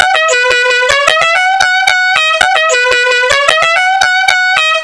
dixie horn
dixie.au